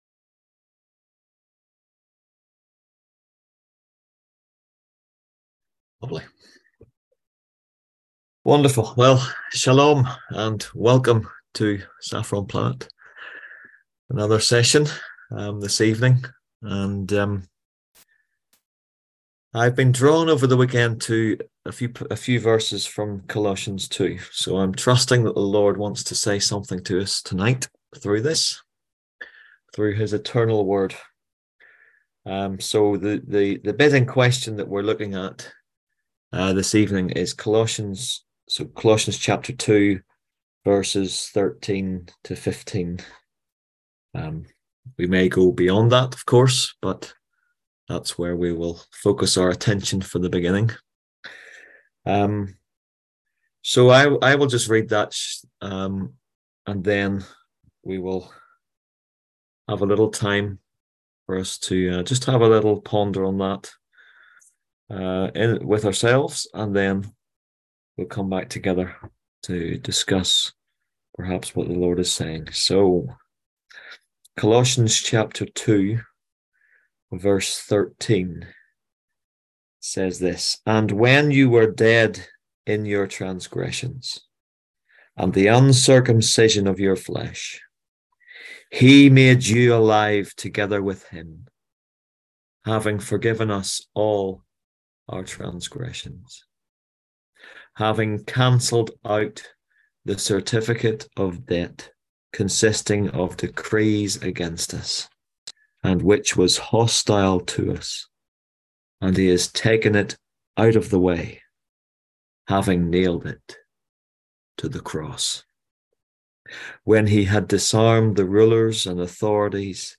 On March 27th at 7pm – 8:30pm on ZOOM ASK A QUESTION – Our lively discussion forum.
On March 27th at 7pm – 8:30pm on ZOOM